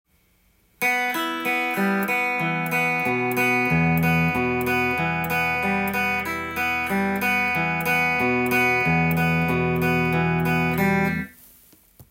2弦強化
２弦強化のアルペジオパターンでは
コードEmを押さえながら必ず２弦をピッキングしたあとに
１番弦から順番に弾いていきます。